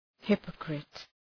Προφορά
{‘hıpəkrıt}